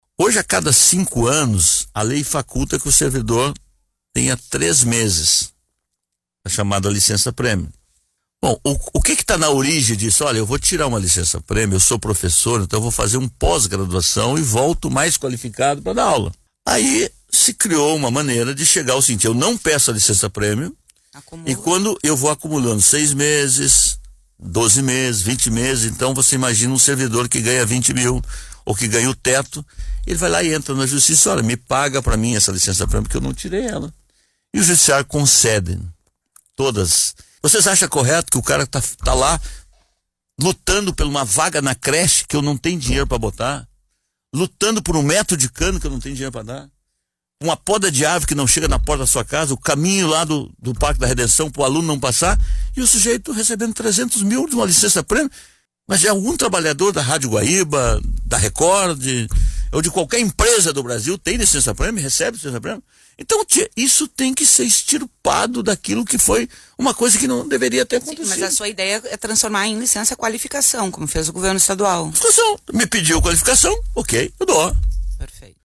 Declarações ocorreram no programa Esfera Pública, da Rádio Guaíba